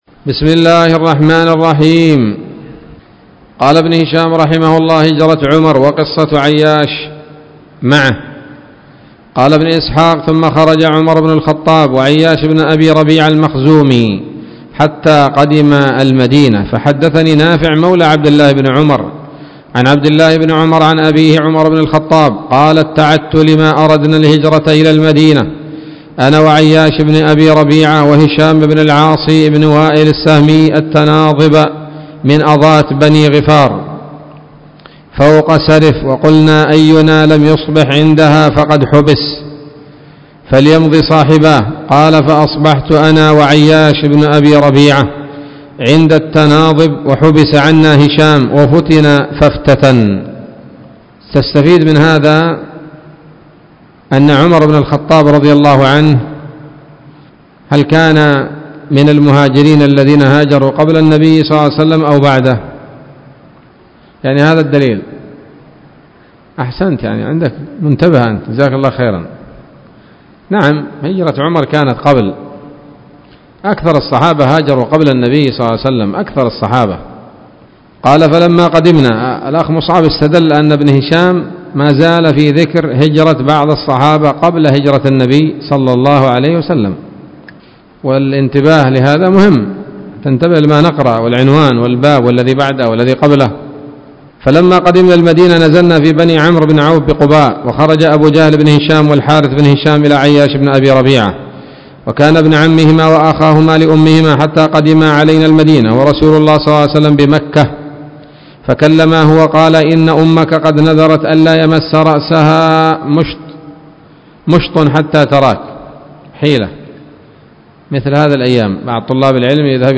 الدرس السبعون من التعليق على كتاب السيرة النبوية لابن هشام